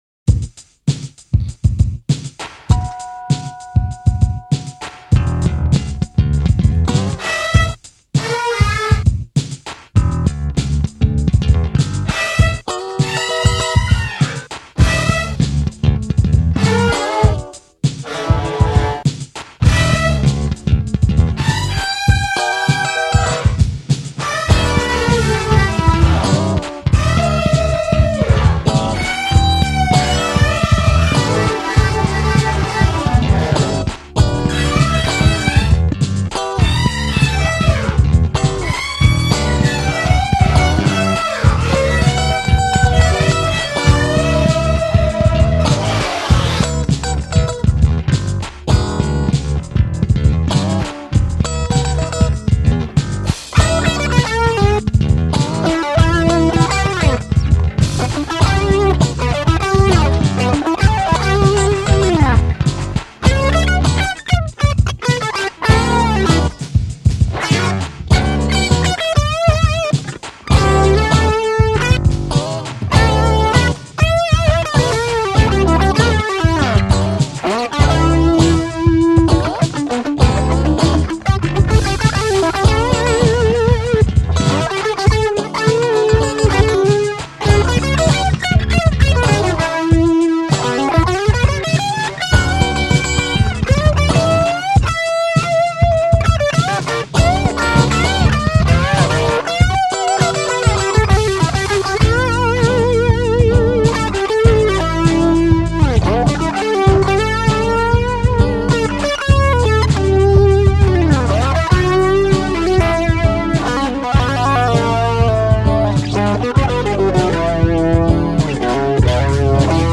e-guitars, e-base, drum-machine, vocals
Original recording: analog 4-track MTR (TASCAM PORTA ONE) in 1987-1988
slow-funk.mp3